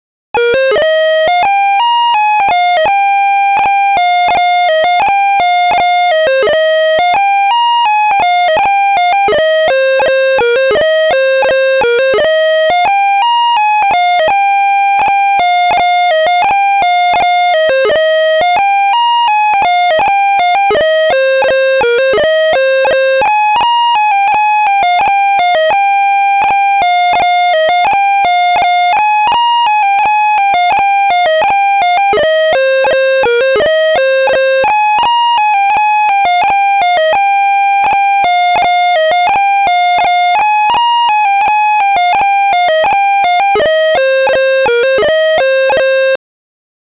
BAGPIPE FILE       FILE